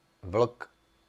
Ääntäminen
IPA: [lu]